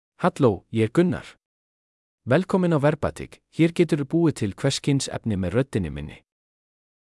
MaleIcelandic (Iceland)
Gunnar is a male AI voice for Icelandic (Iceland).
Voice sample
Male
Gunnar delivers clear pronunciation with authentic Iceland Icelandic intonation, making your content sound professionally produced.